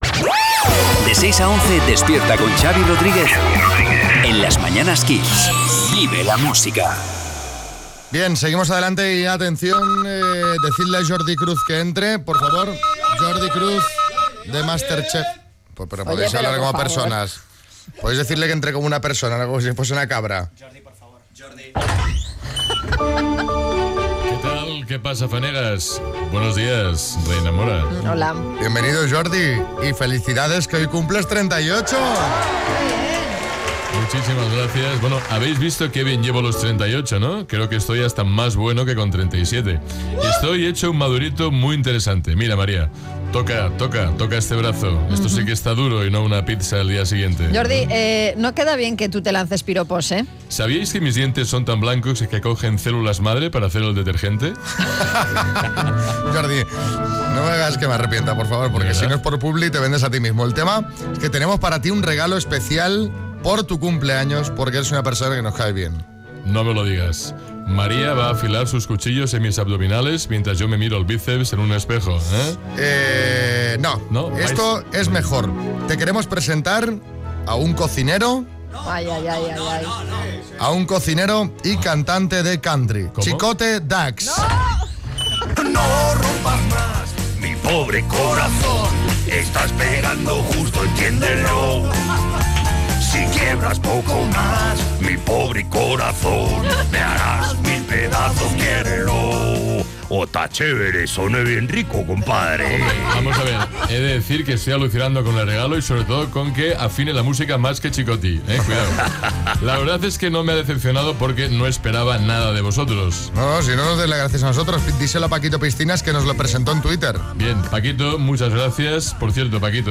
Nuestro chef favorito cumple hoy 38 años y le felicitamos en directo en el programa.